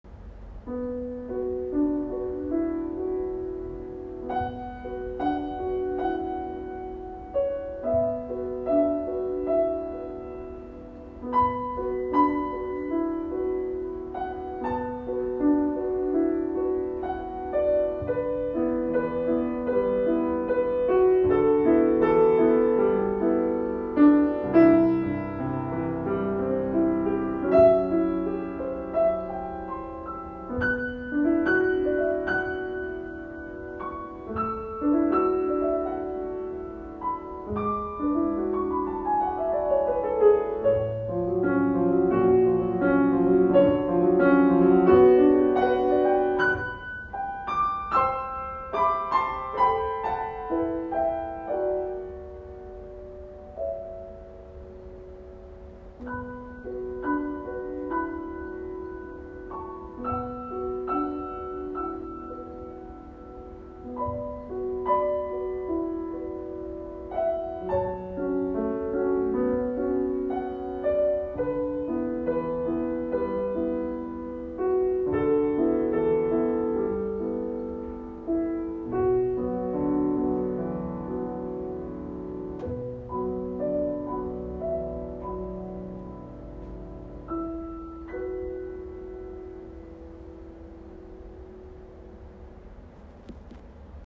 ENTRÉE MUSICALE